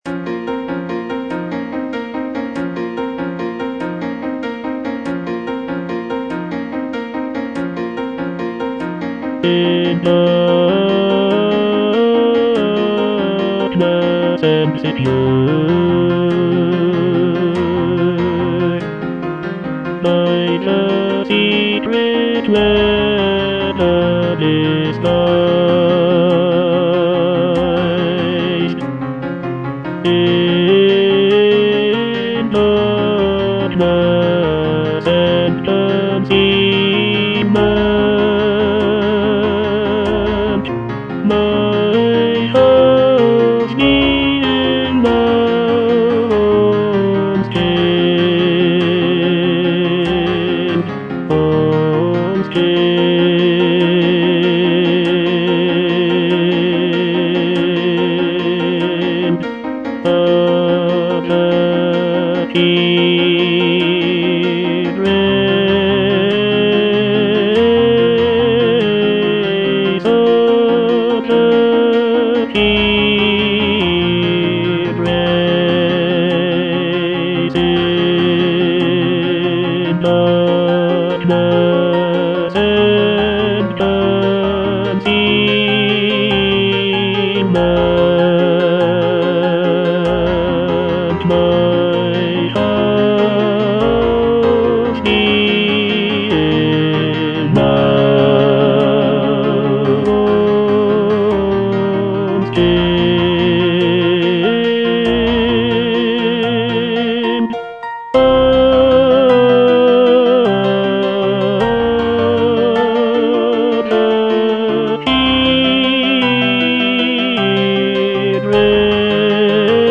tenor II) (Voice with metronome
choral work